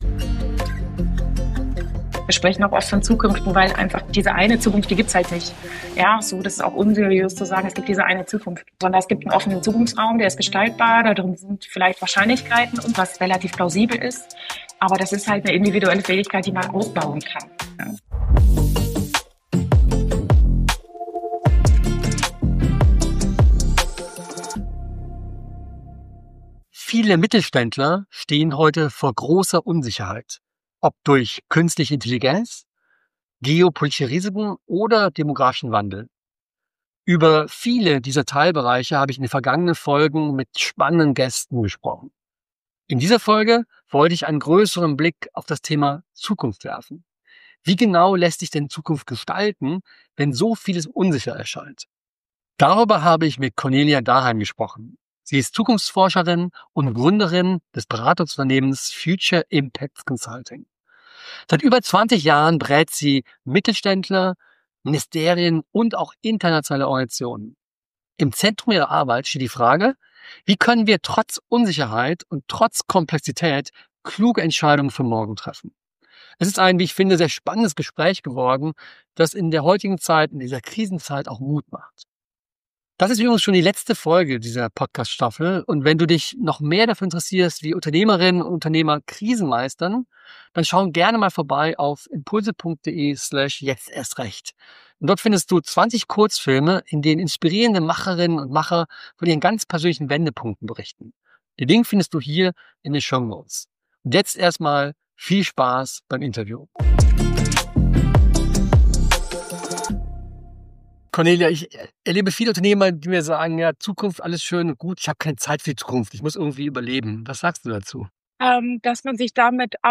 Doch wie kann man sich auf das Kommende einstellen, wenn so vieles unsicher scheint? Im Interview erklärt die Expertin, wie Unternehmen Zukunftsszenarien entwickeln, auf die sie hinarbeiten können.